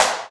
• Crisp Snare Drum G Key 18.wav
Royality free steel snare drum sound tuned to the G note. Loudest frequency: 2447Hz
crisp-snare-drum-g-key-18-SGc.wav